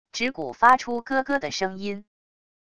指骨发出咯咯的声音wav音频